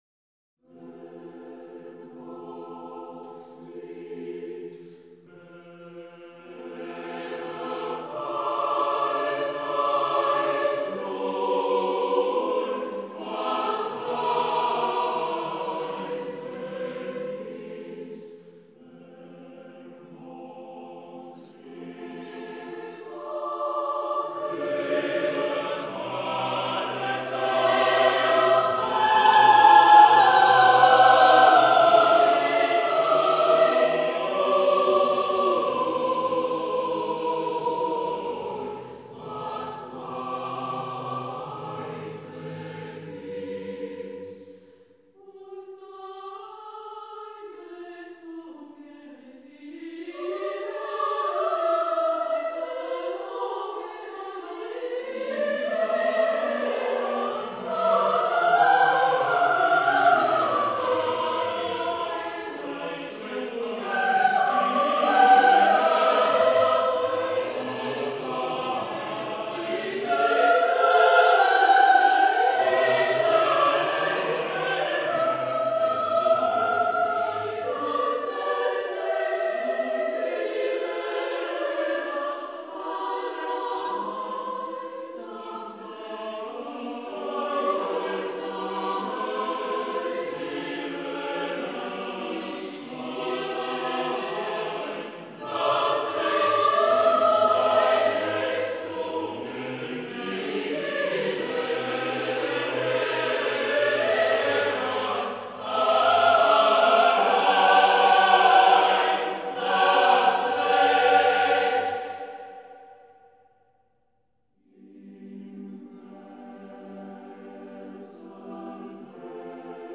interpretată de Corul Filarmonicii „George Enescu”
înregistrare specială realizată în Studioul Radio T8